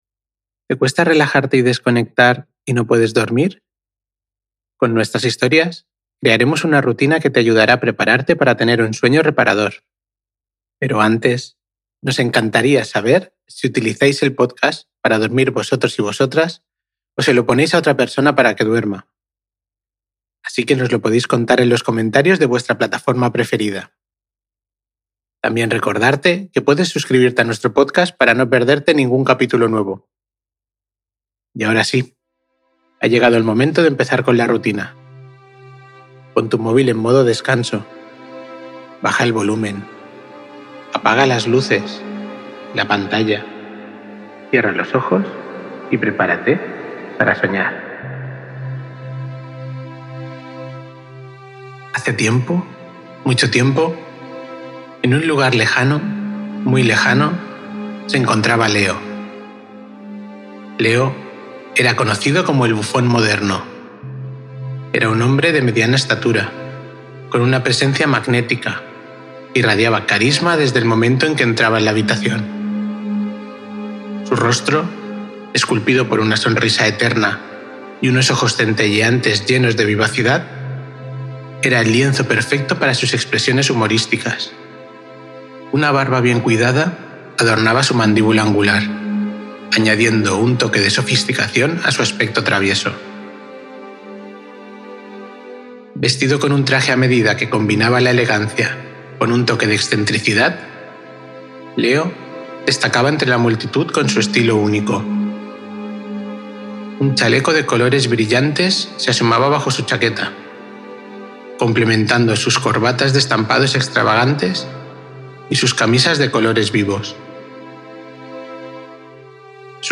Sumérgete en un mundo de misterio y esperanza mientras te dejamos arrullar con la historia de Leo, el bufón moderno que descubre la verdad oculta en las sombras de Arcadia. Una narrativa perfecta para conciliar el sueño con personajes fascinantes y un mensaje inspirador.